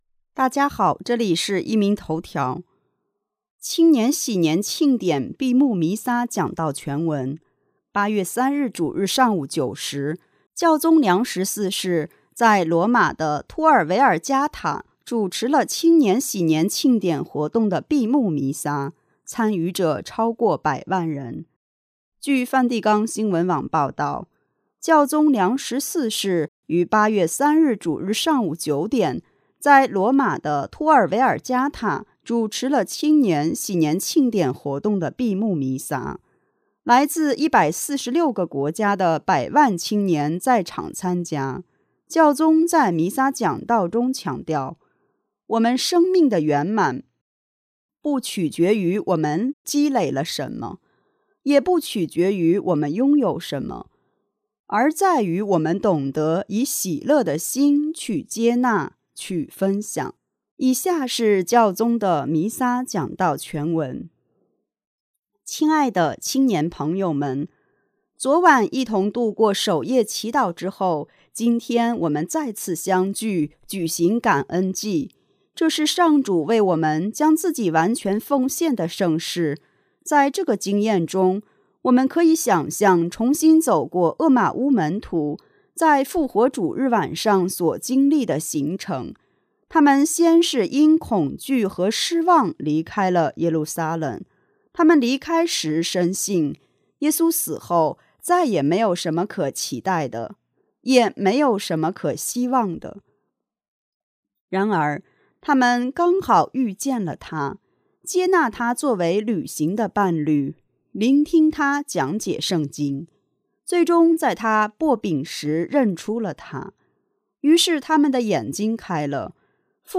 8月3日主日上午9时，教宗在罗马的托尔维尔加塔主持了青年禧年庆典活动的闭幕弥撒，参与者超过百万人。